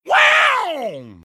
mouth-guitar_07